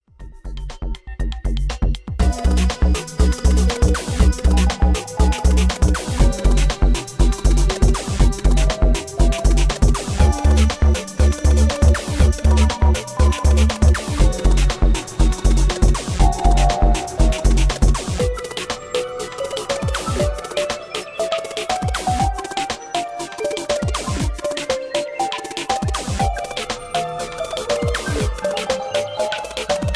Cinematic Ambient with feel of Mystery